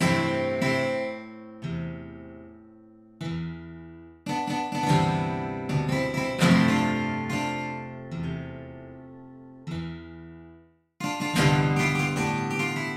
那里的吉他
描述：原声或管弦乐
Tag: 74 bpm Acoustic Loops Guitar Acoustic Loops 2.18 MB wav Key : Unknown